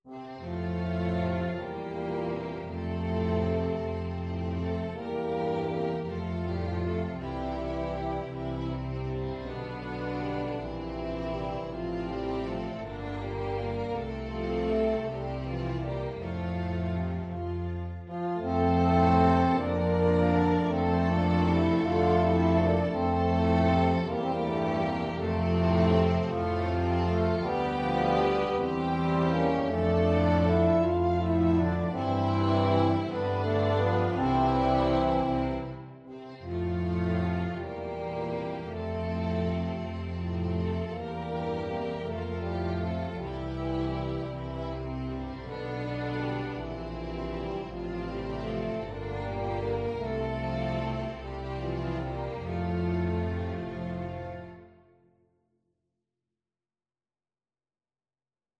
EuphoniumEuphonium (Bass Clef)
Tuba
Andante
6/8 (View more 6/8 Music)